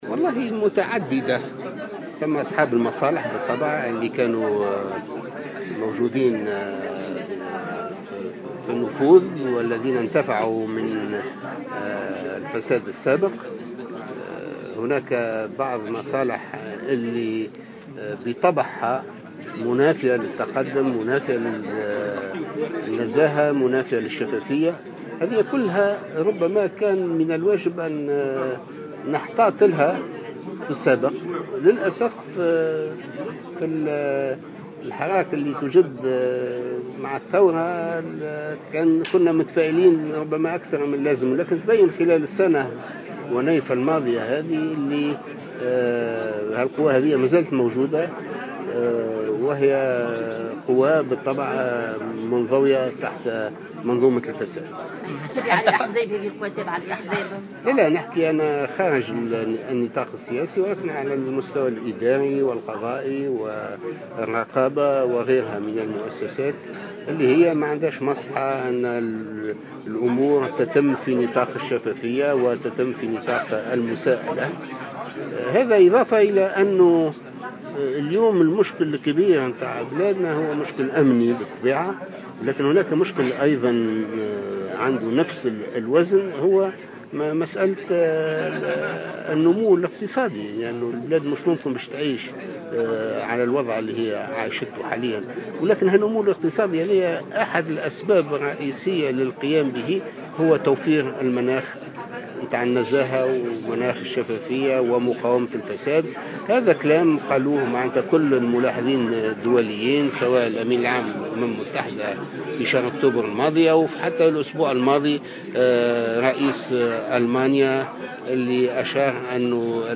قال سمير العنابي رئيس الهيئة الوطنية لمكافحة الفساد، في تصريح إعلامي اليوم...